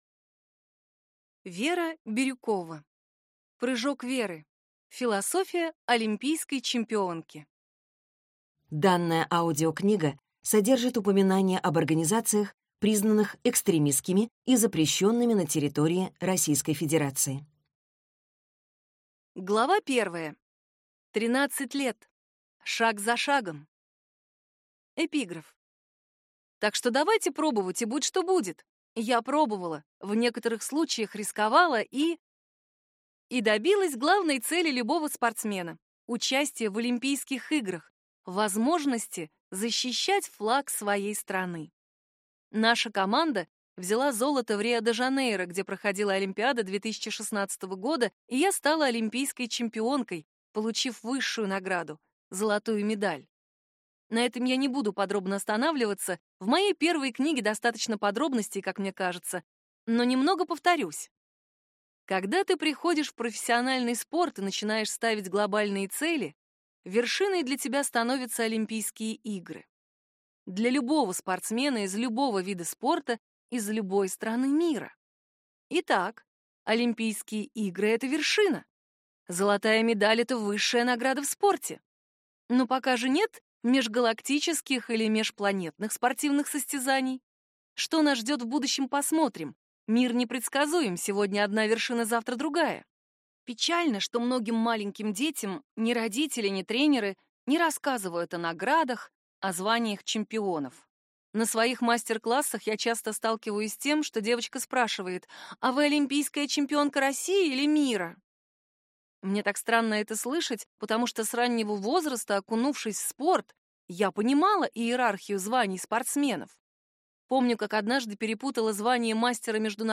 Аудиокнига Прыжок Веры. Философия олимпийской чемпионки | Библиотека аудиокниг